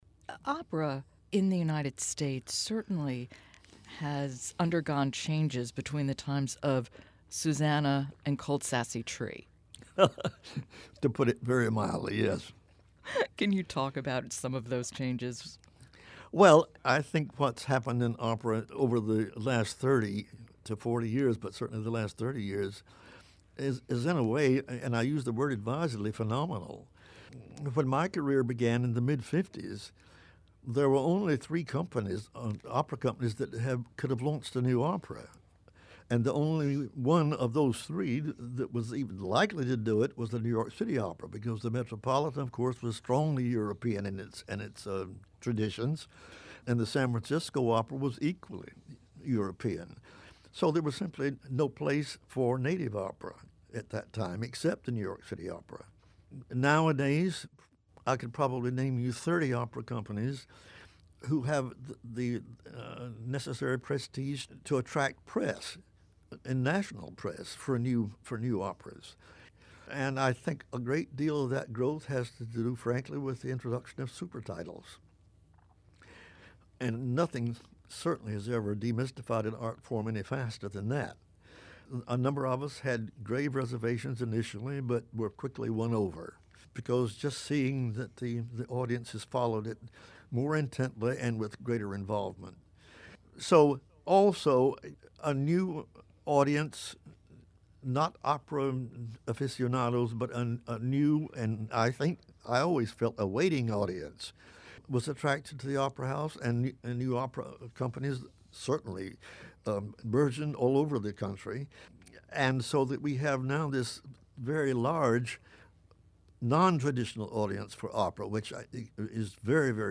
Transcript of interview with Carlisle Floyd